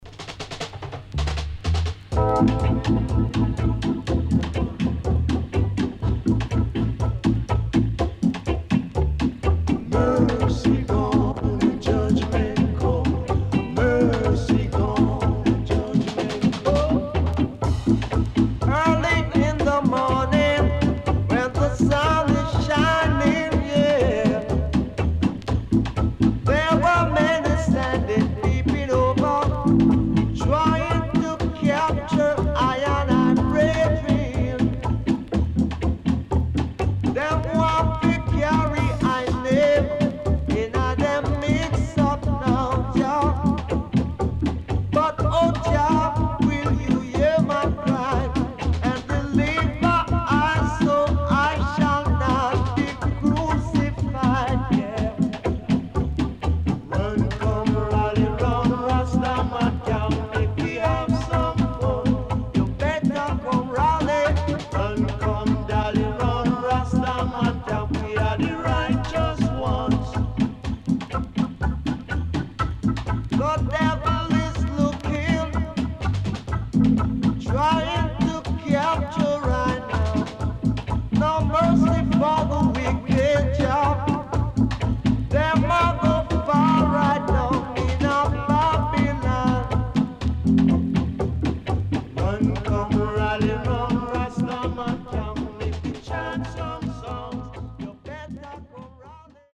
Great Roots Vocal